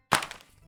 DroppingBoneItem.ogg